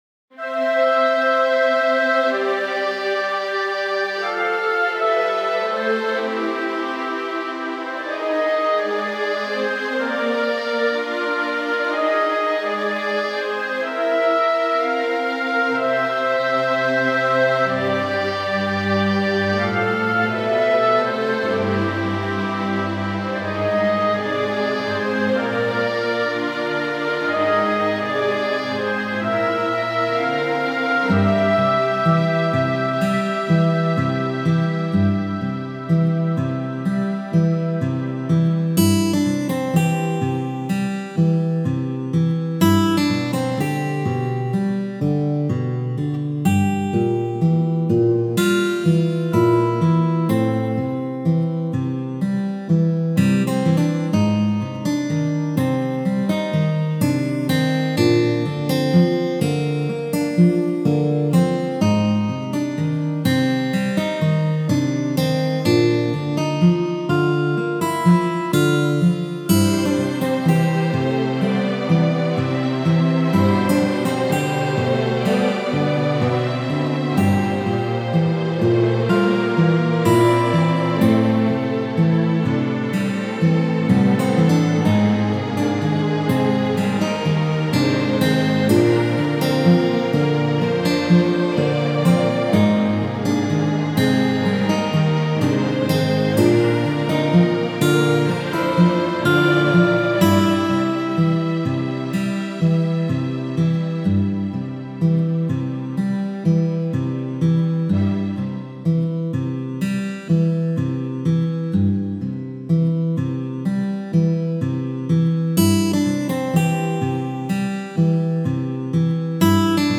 のどかな街の日常の一風景。激しい戦闘から解放された戦士たちを迎える、宿のある村の感じ